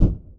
EXPLOSION_Subtle_Poof_01_stereo.wav